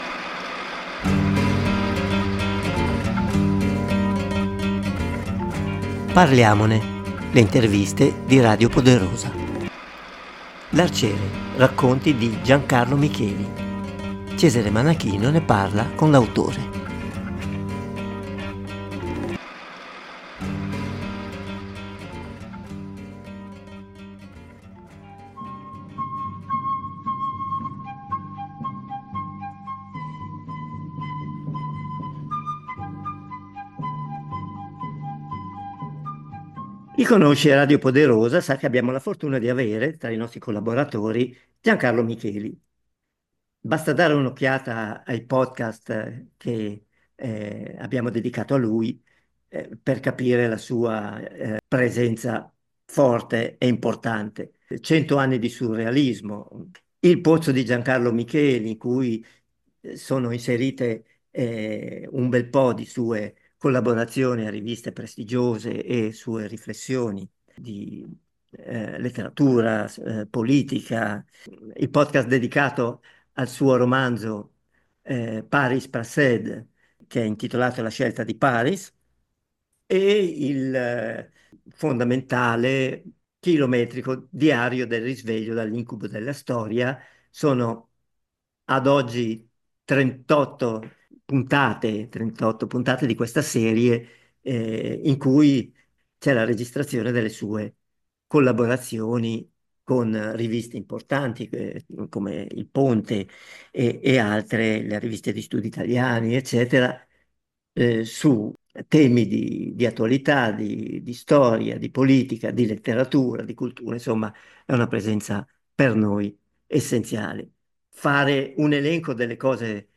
Parliamone - Le interviste